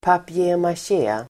Ladda ner uttalet
Folkets service: papier-maché papier-maché substantiv (franska), papier-mâché [French] Uttal: [papjemasj'e] Böjningar: papier-machén Definition: pappersmassa som används till modeller papier-mâché substantiv, papier-maché